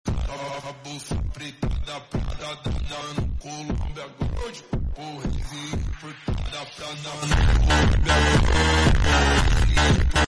Best overtake ever in racing sound effects free download